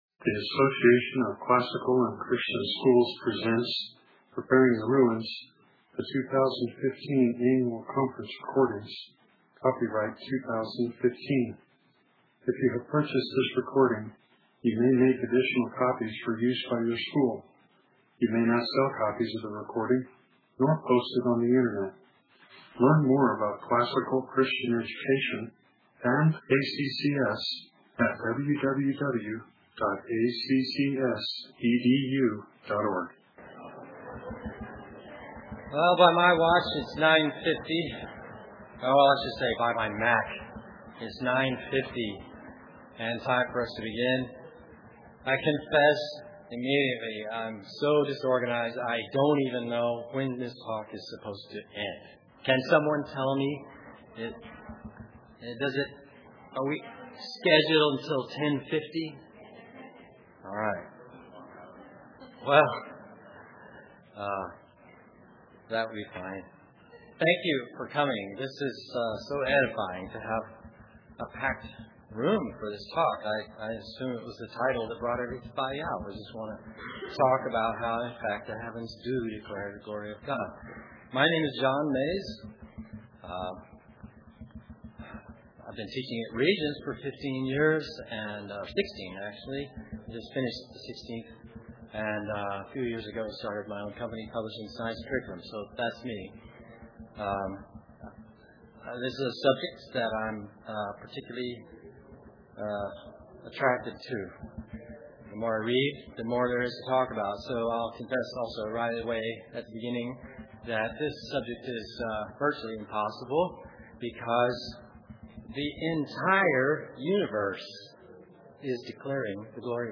2015 Workshop Talk | 1:14:27 | All Grade Levels, Bible & Theology